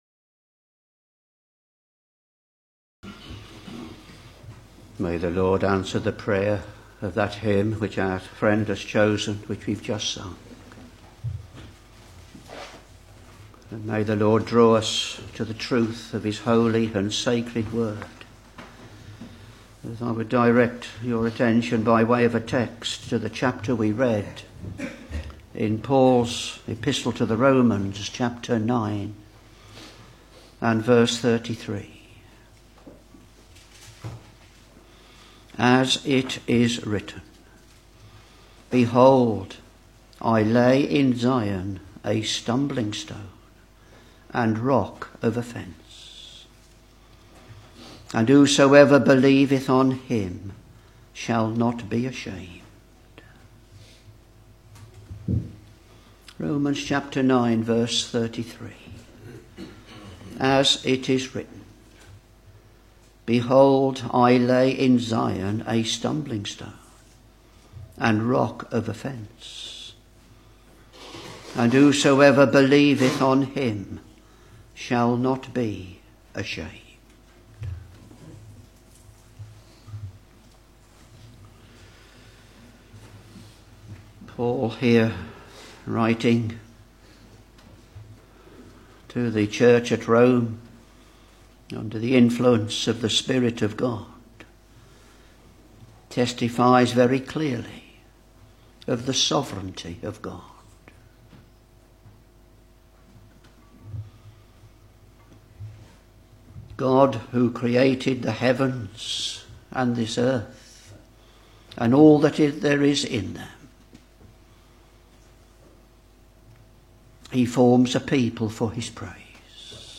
Sermons Romans Ch.9 v.33